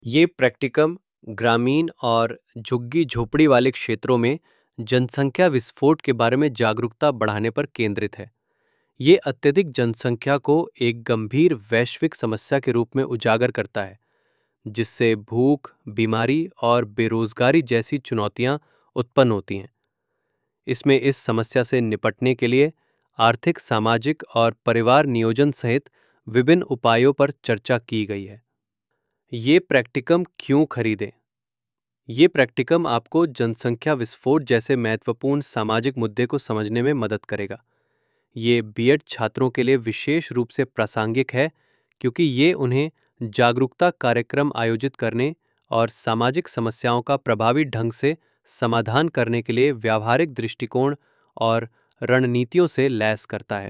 A short audio explanation of this file is provided in the video below.